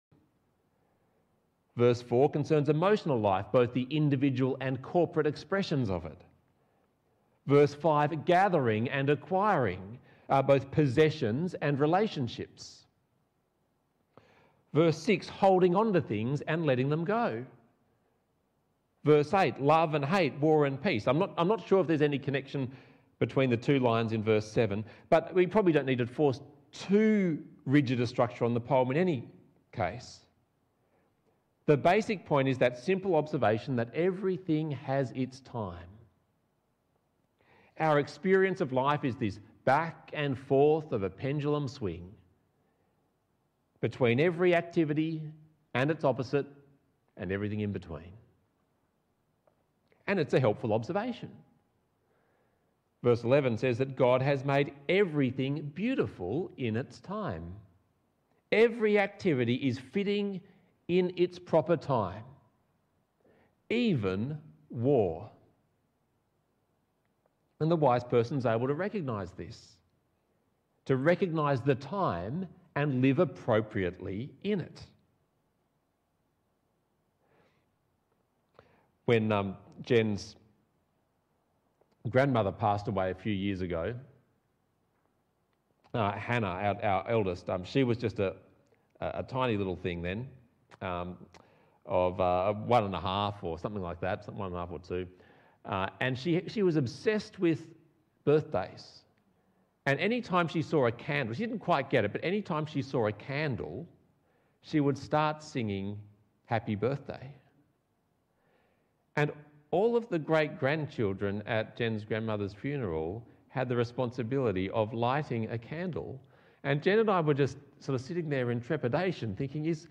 Passage: Ecclesiastes 3 Talk Type: Bible Talk